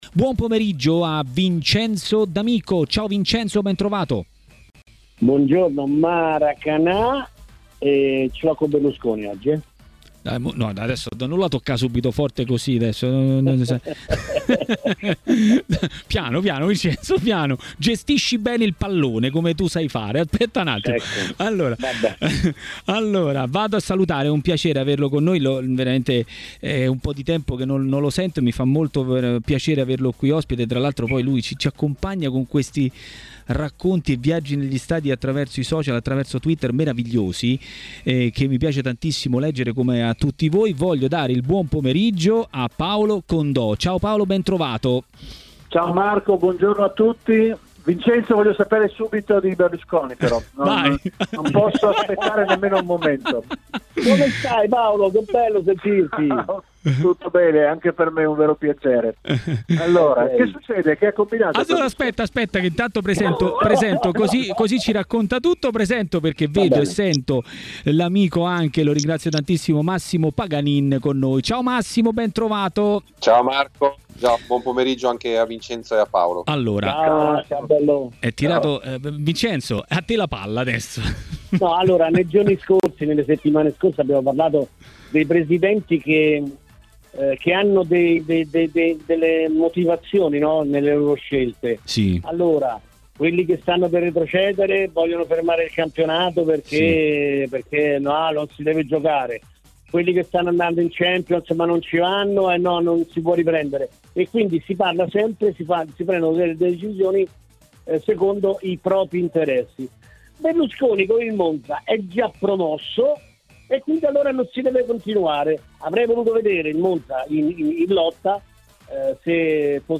Il giornalista Paolo Condò ha commentato a Maracanà, nel pomeriggio di TMW Radio, le notizie del giorno.